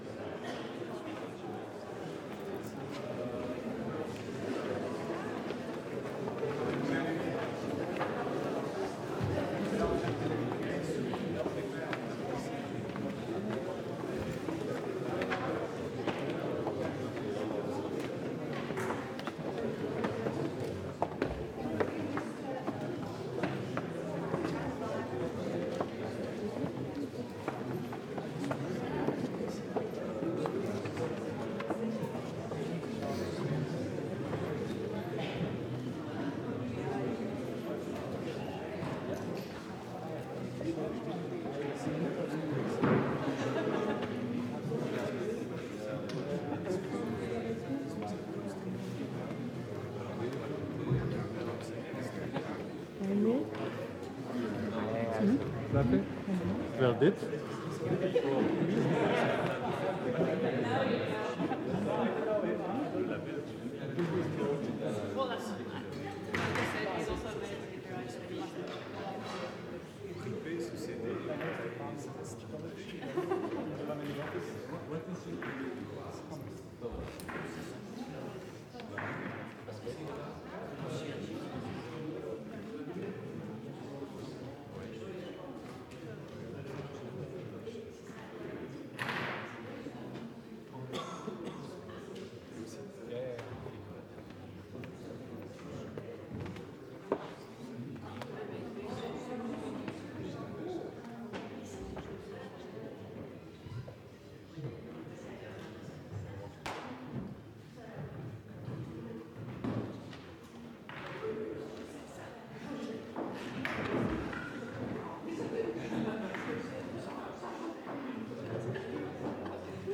bgm_crowd_ambience.ogg